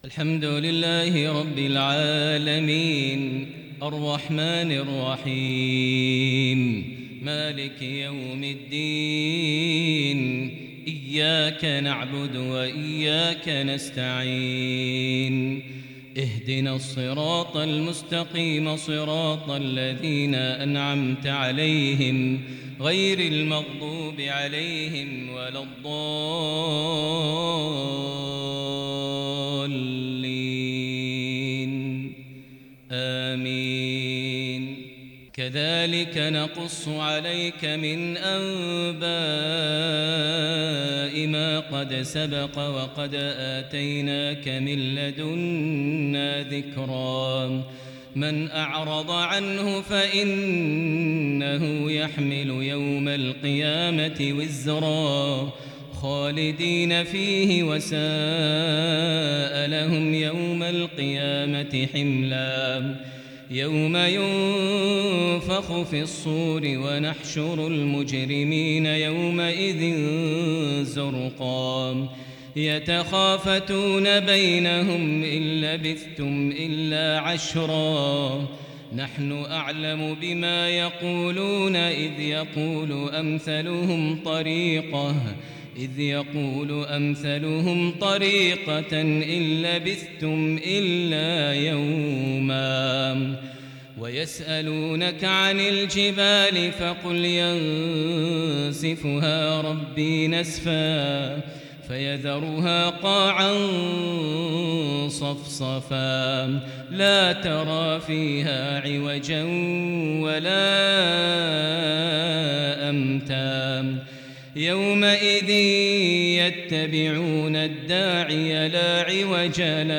صلاة المغرب للشيخ ماهر المعيقلي 2 ذو الحجة 1442 هـ
تلاوة من سورتي طه و المنافقون.....